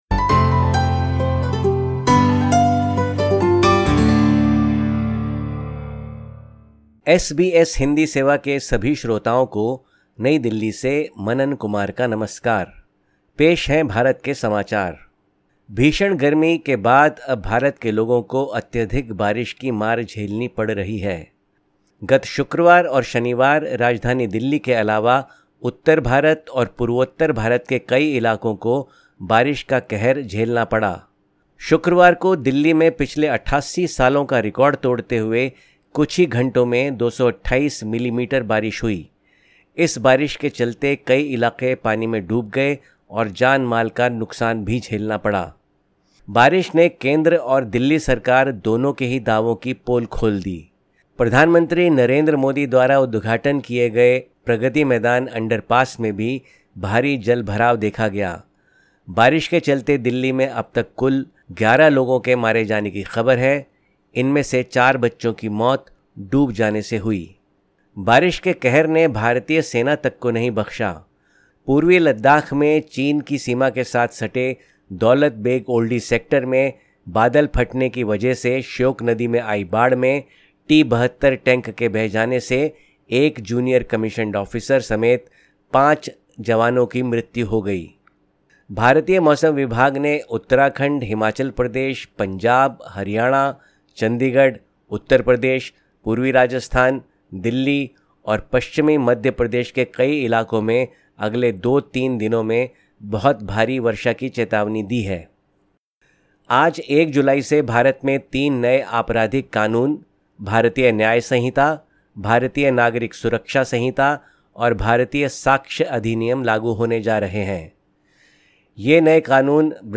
Listen to the latest SBS Hindi news from India. 01/07/2024